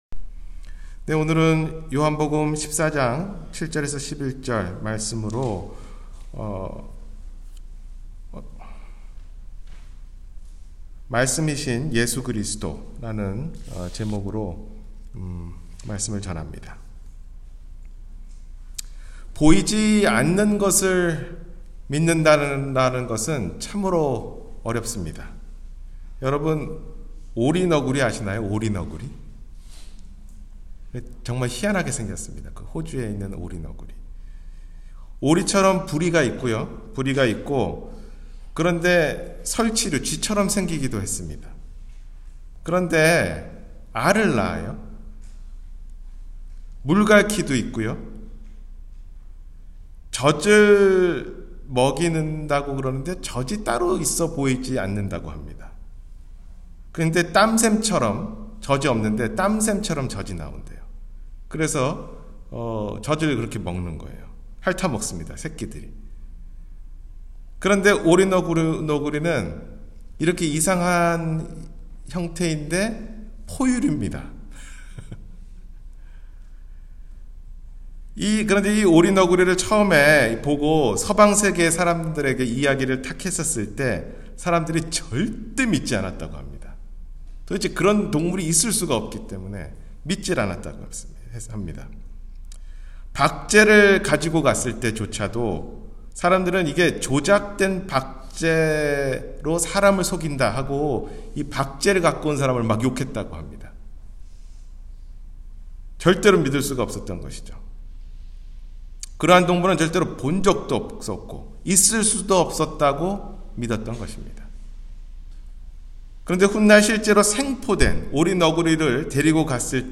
말씀이신 예수 그리스도 – 주일설교